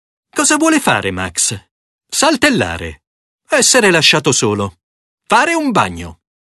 dog
sad